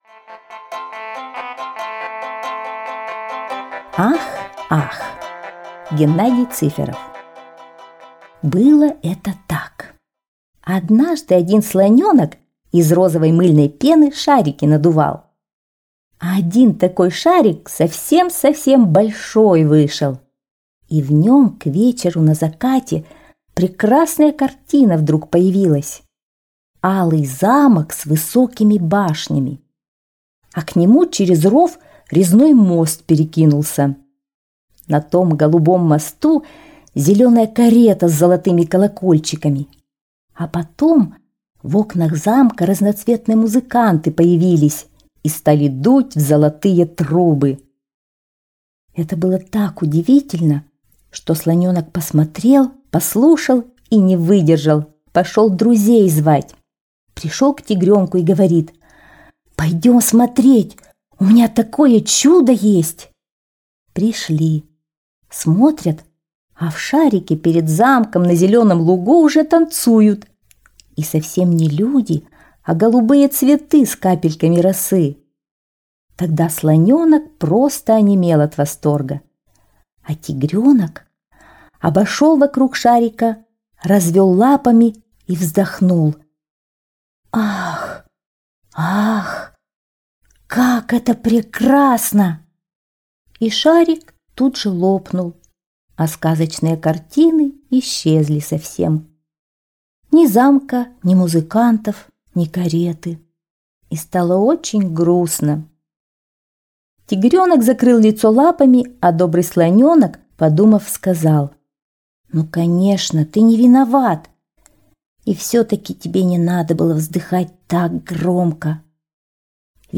- аудиосказка Цыферова Г.М. Интересная история про волшебные мыльные пузыри, которые надувал слоненок.